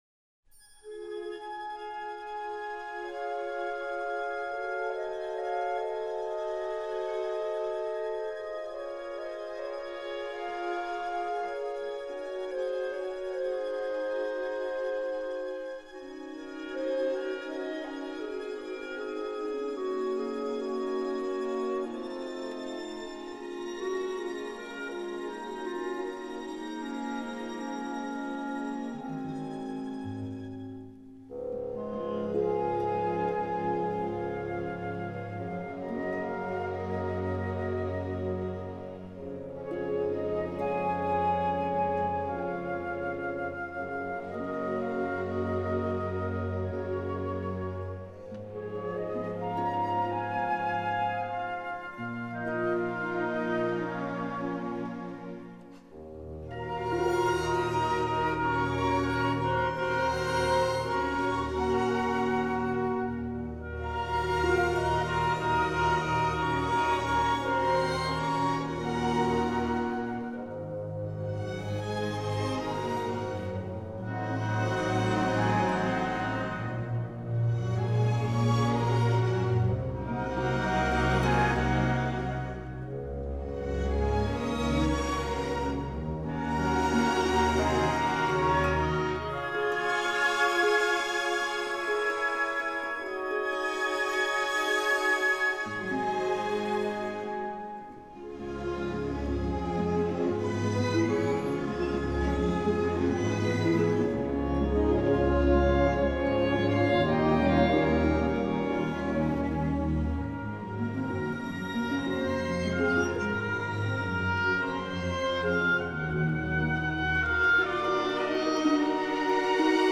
این قطعه اوج سبک امپرسیونیستی در موسیقی است. ملودی نرم و ظریف پیانو، حس آرامش و تعمق را منتقل میکند.